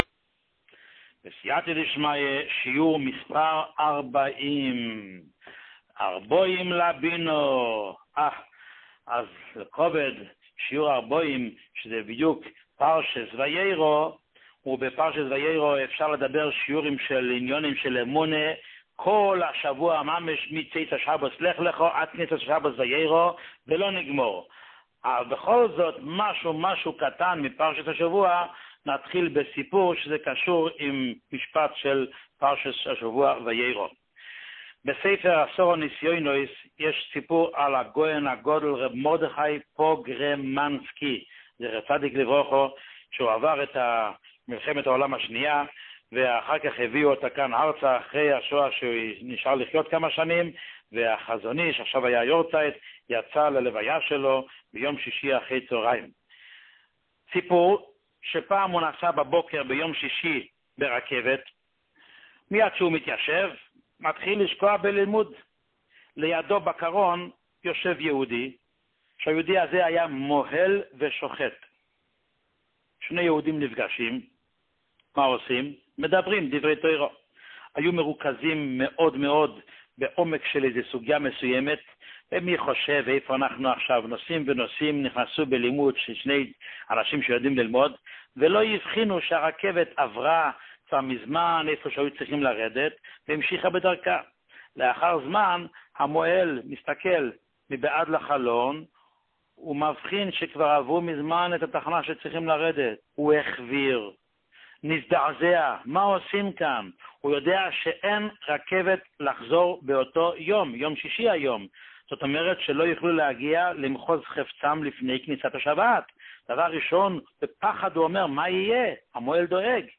שיעור 40